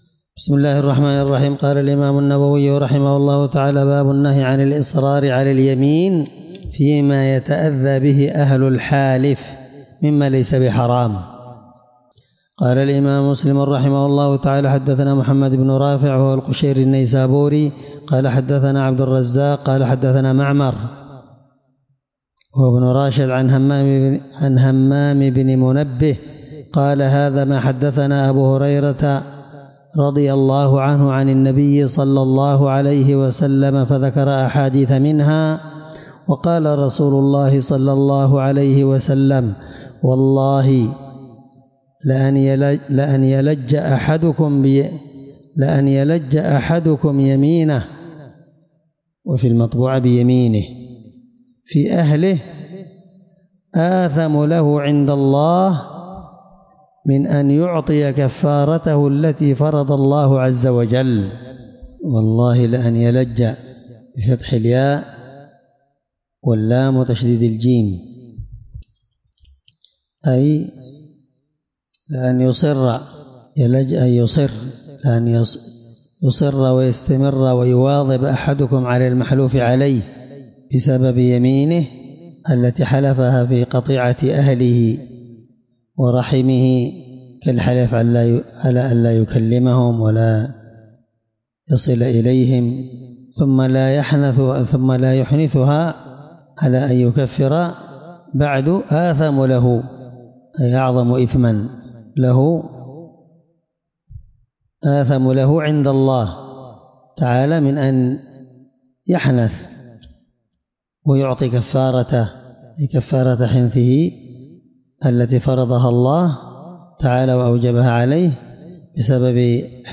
الدرس8من شرح كتاب الأيمان حديث رقم(1655) من صحيح مسلم